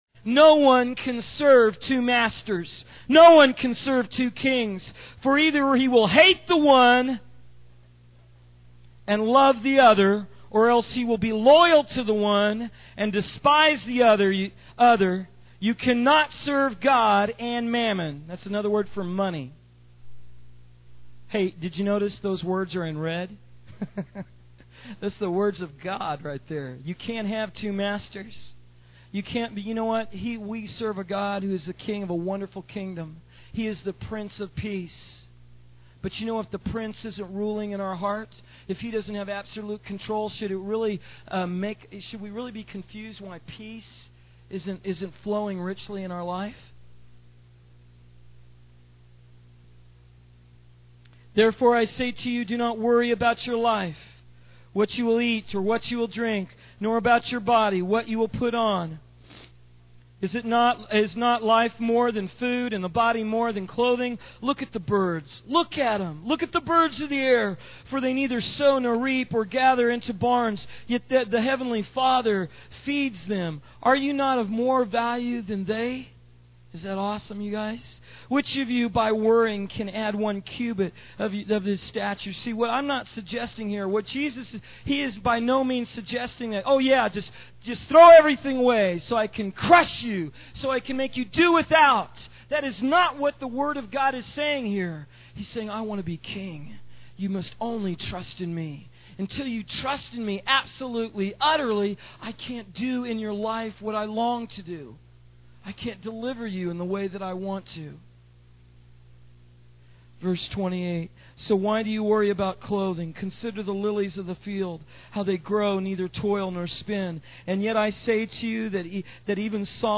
In this sermon, the preacher focuses on the story of Zacchaeus and his encounter with Jesus. He emphasizes the importance of recognizing Jesus as the King and being hungry for Him.